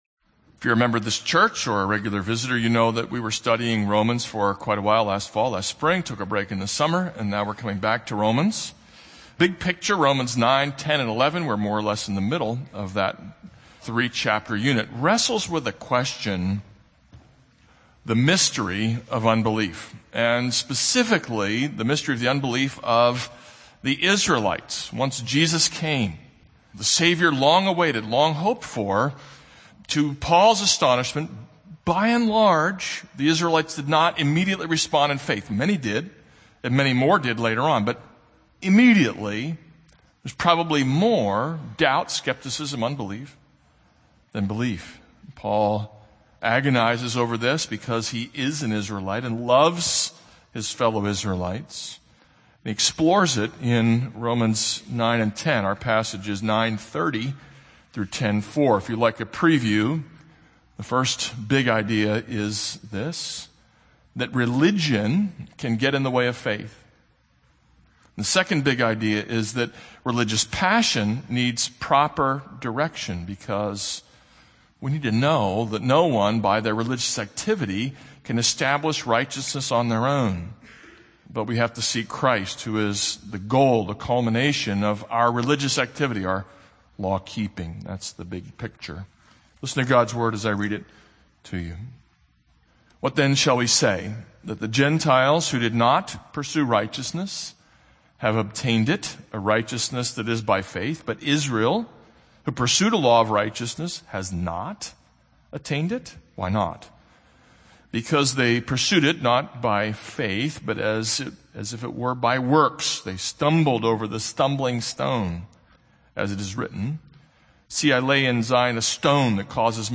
This is a sermon on Romans 9:30-10:4.